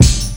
• Old School Acoustic Kickdrum Sample F Key 308.wav
Royality free kick one shot tuned to the F note. Loudest frequency: 1692Hz
old-school-acoustic-kickdrum-sample-f-key-308-ISn.wav